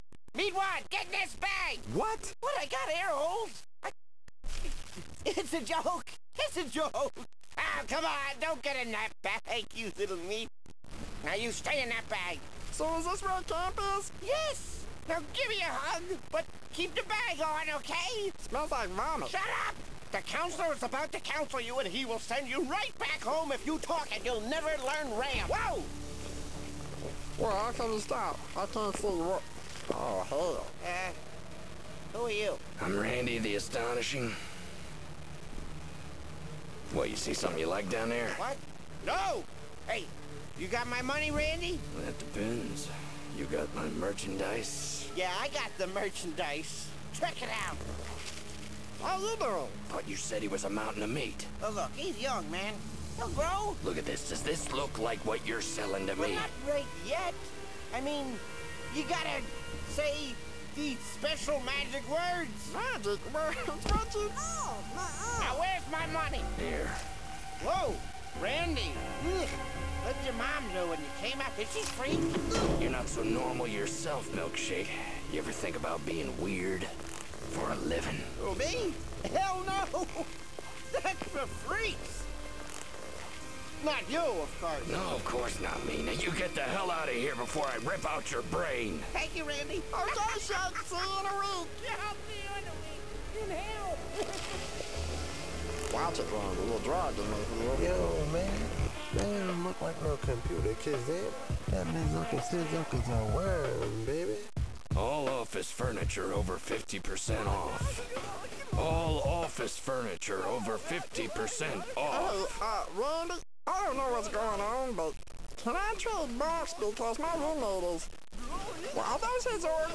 Circus sound clip